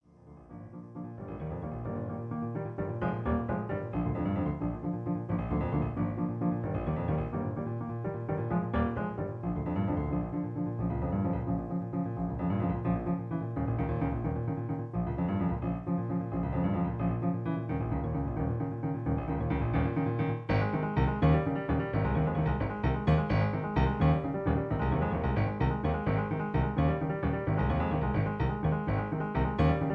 In A flat. Piano Accompaniment